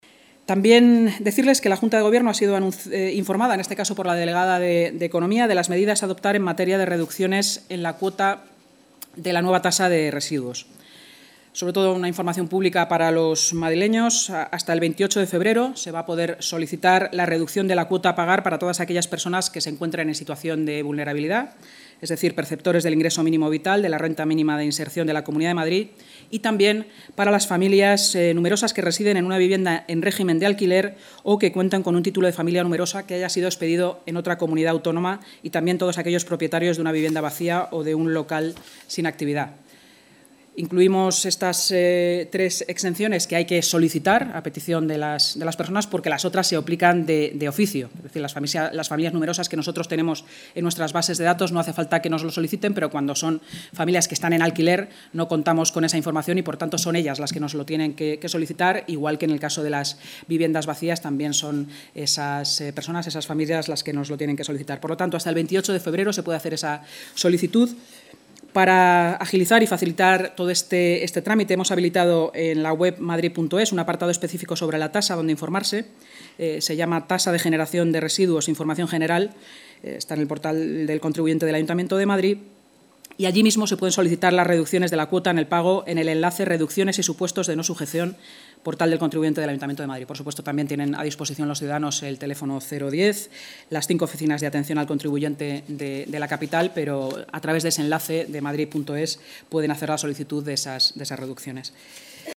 Nueva ventana:Inma Sanz, en rueda de prensa: plazo para solicitar las reducciones en la cuota